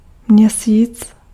Ääntäminen
Ääntäminen France: IPA: [ɛ̃ mwɑ] Tuntematon aksentti: IPA: /mwa/ Haettu sana löytyi näillä lähdekielillä: ranska Käännös Ääninäyte Substantiivit 1. měsíc {m} Suku: m .